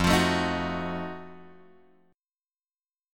F7b9 chord {1 x 3 2 4 2} chord